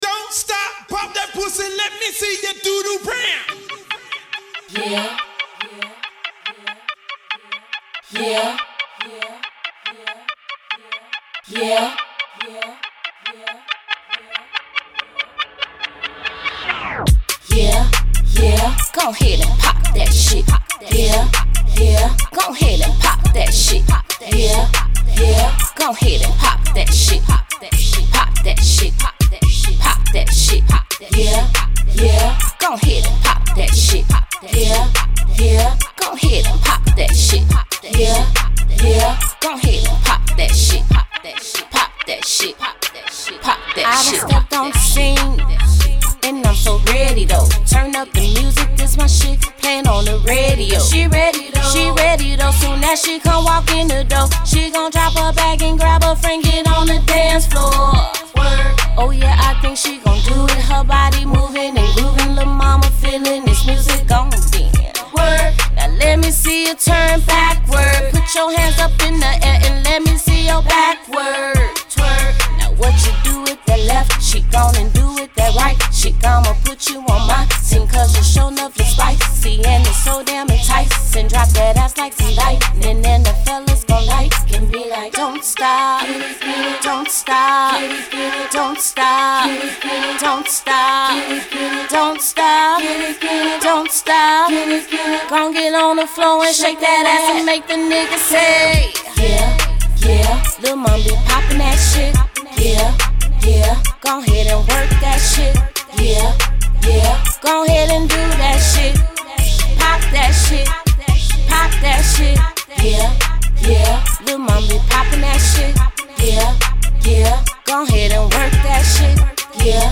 RnB
Description : R&B/HIP-HOP DANCE. CLUB